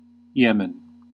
1. ^ /ˈjɛmən/
En-us-Yemen.ogg.mp3